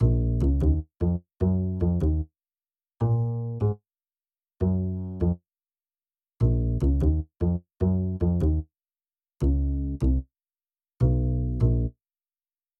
深度低音
描述：直立式低音提琴。
Tag: 75 bpm Jazz Loops Bass Guitar Loops 2.15 MB wav Key : Unknown